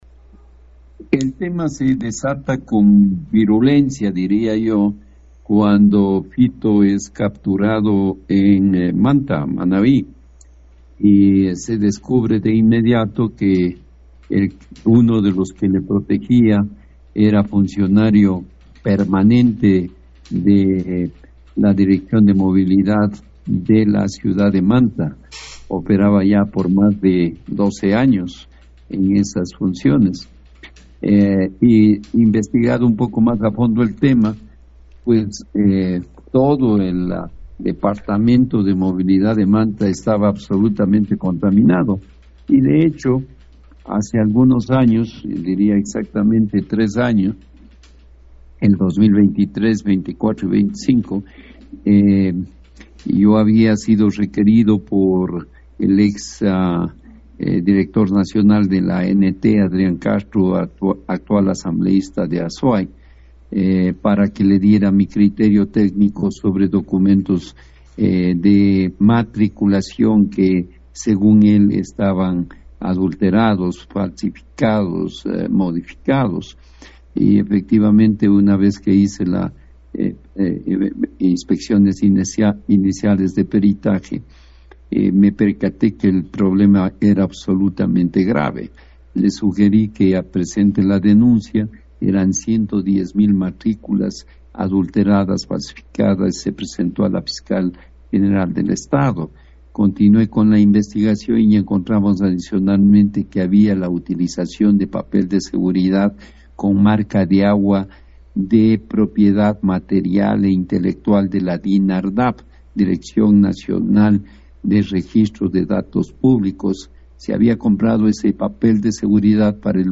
una entrevista en Nina radio de Pastaza